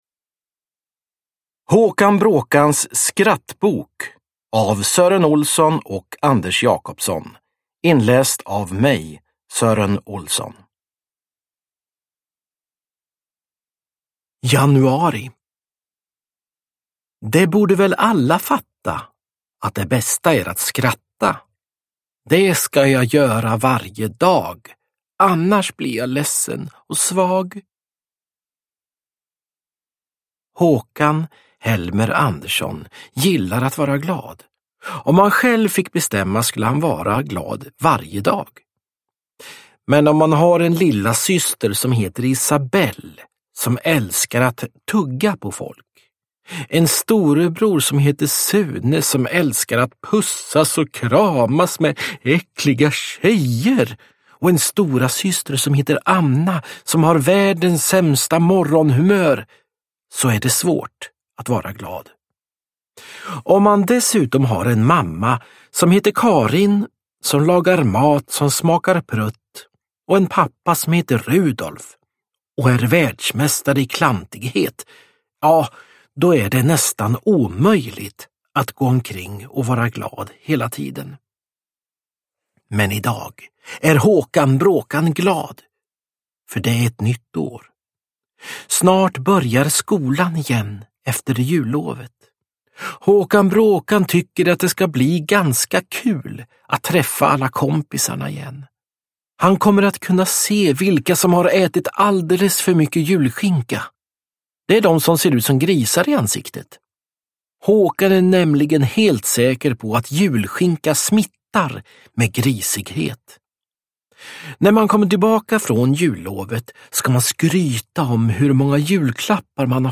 Håkan Bråkans skrattbok – Ljudbok – Laddas ner
Uppläsare: Sören Olsson